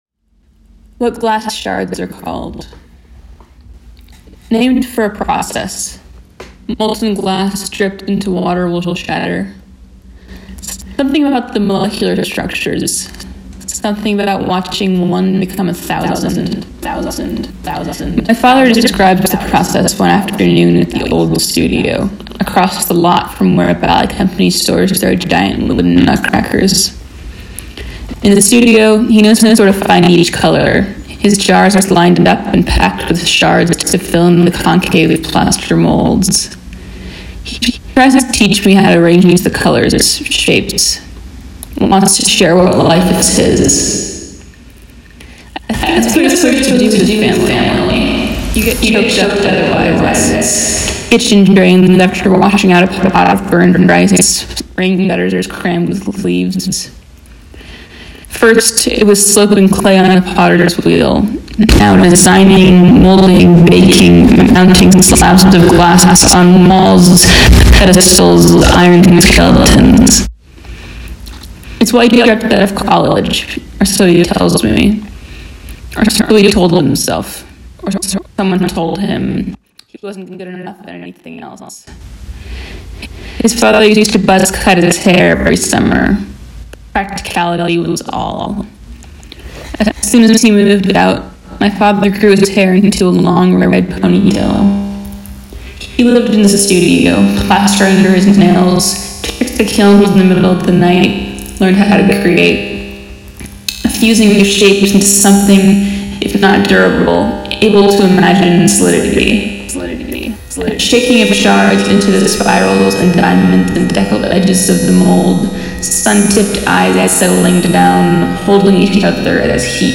"What Glass Shards Are Called" altered audio read through
spoken text recorded